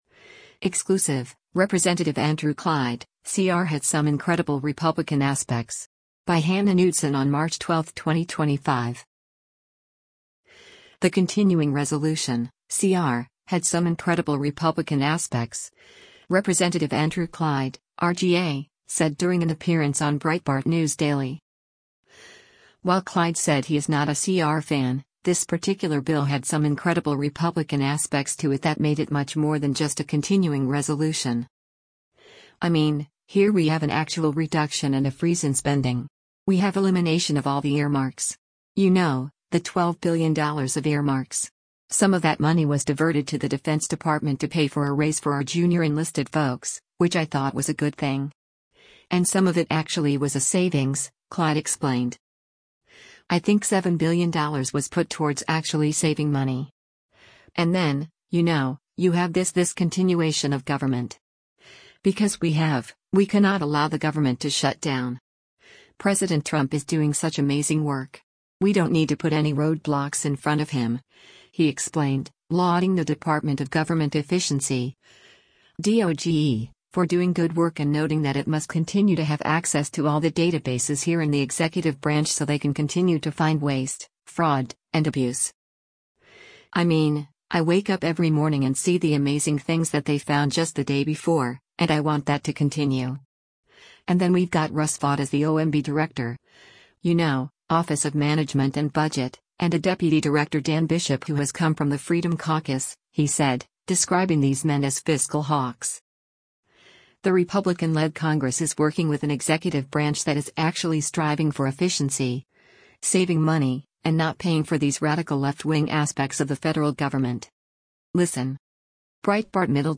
The Continuing Resolution (CR) had “some incredible Republican aspects,” Rep. Andrew Clyde (R-GA) said during an appearance on Breitbart News Daily.